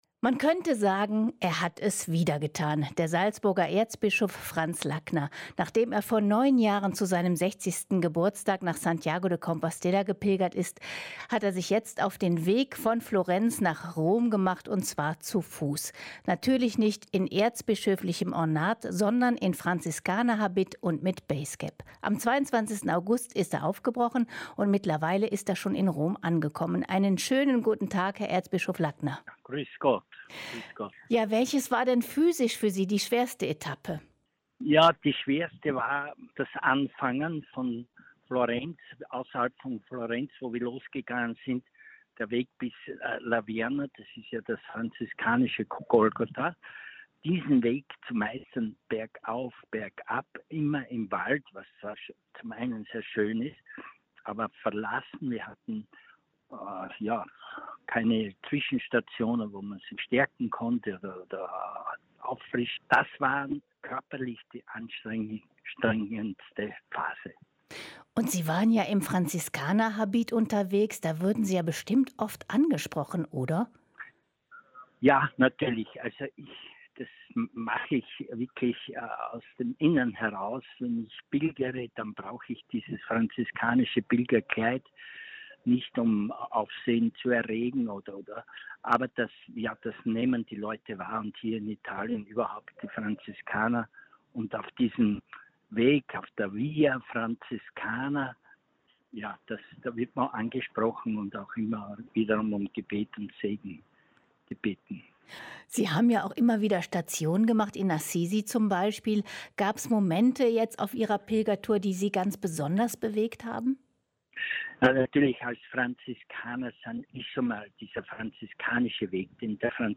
Ein Interview mit Franz Lackner (Erzbischof von Salzburg)